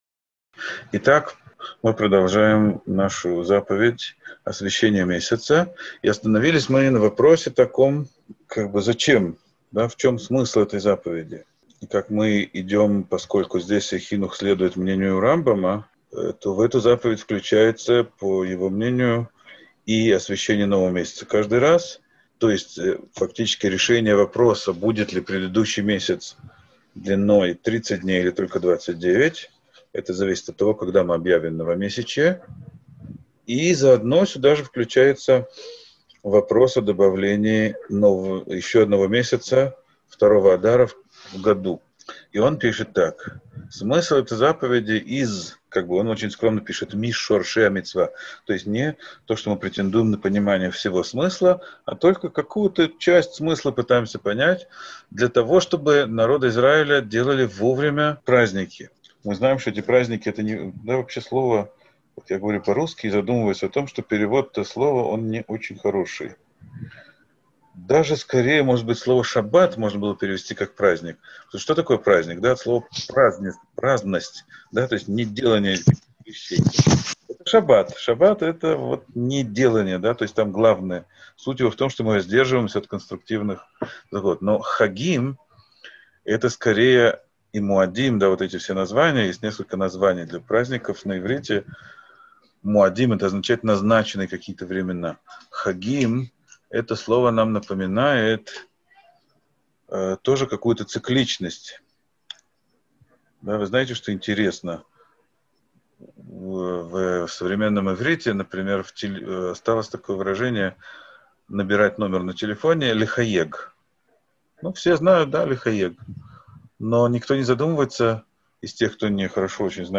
Урок 8.